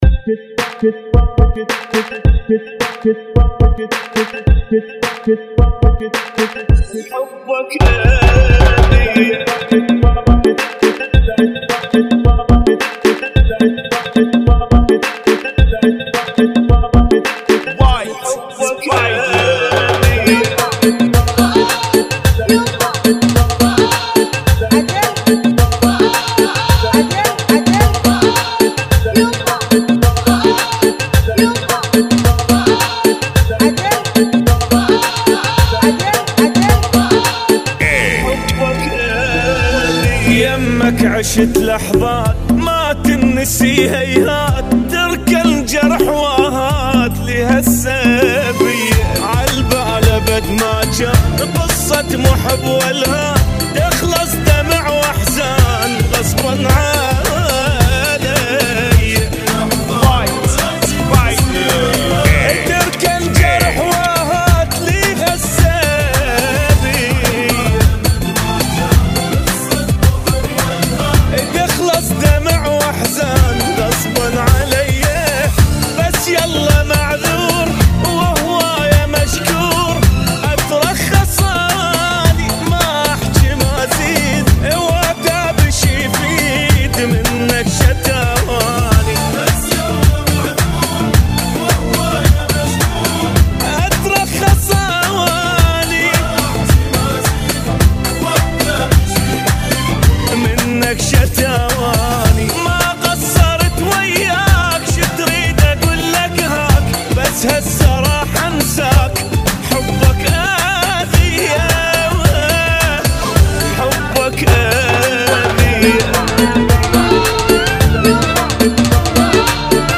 Funky Mix [ 108 Bpm ]